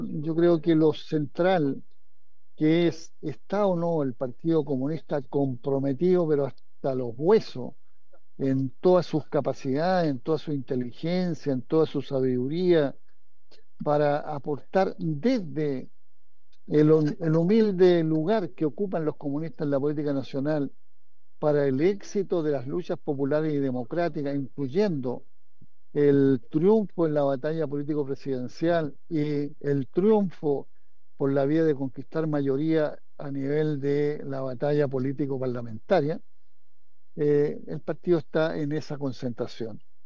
Desde la romería que se realizó el domingo en el Cementerio General en homenaje del ex presidente del Partido Comunista, Guillermo Teillier, el actual timonel de la colectividad, Lautaro Carmona, compartió palabras de reconocimiento.